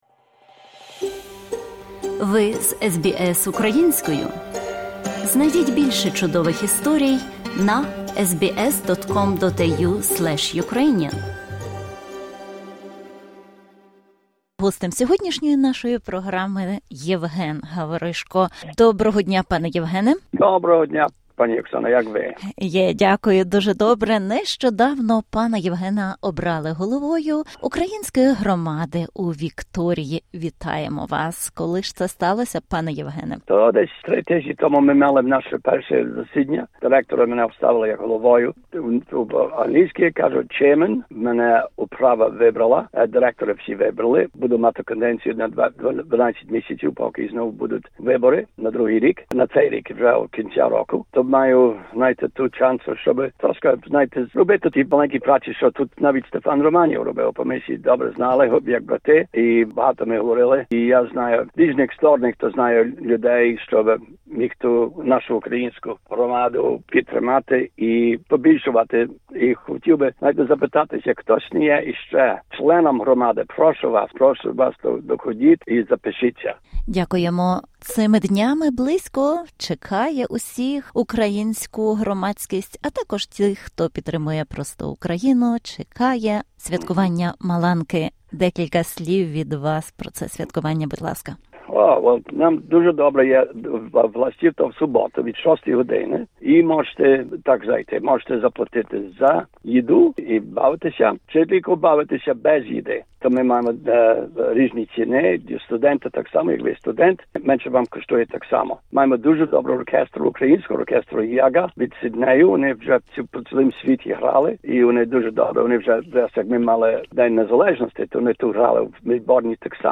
Це інтерв’ю розповідає про підготовку до майбутньої Мельбурнської Маланки 2025, яскравого свята української культури.